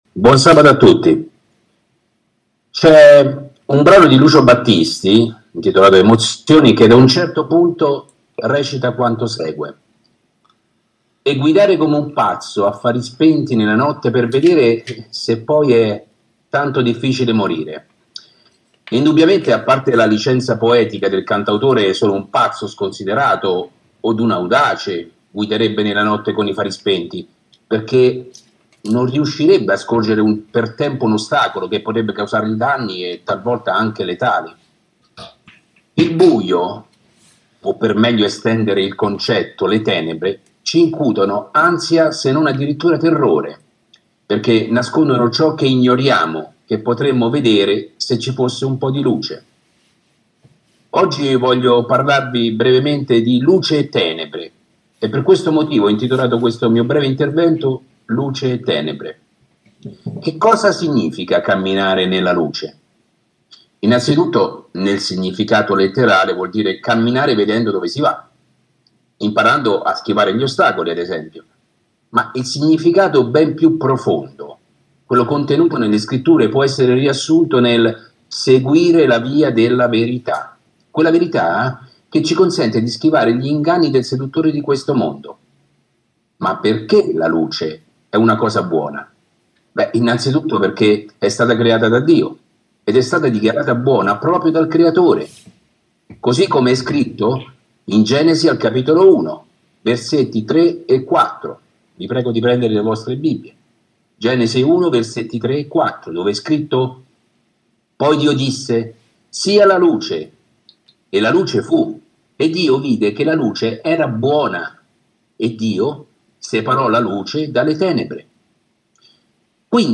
Sermonetto